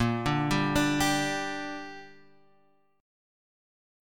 A#6 chord